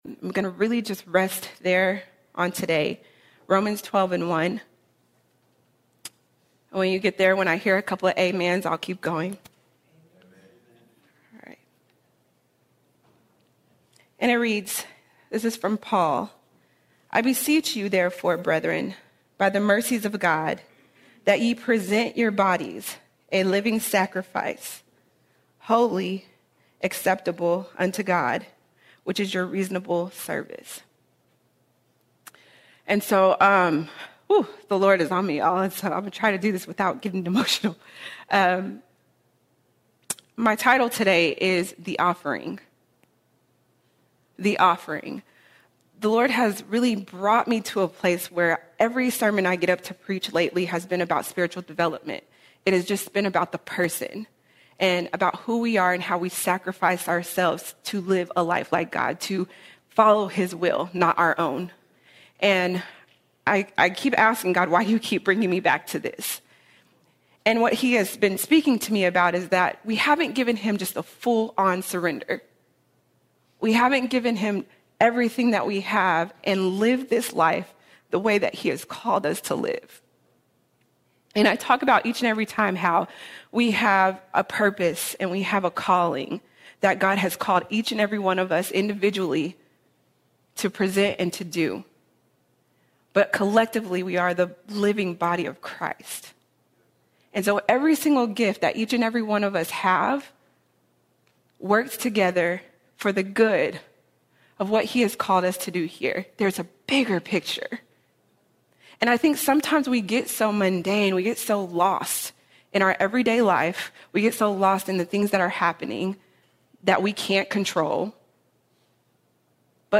20 April 2026 Series: Sunday Sermons All Sermons The Offering The Offering Because of Jesus Christ’s love and sacrifice, we are called to offer our lives fully to God.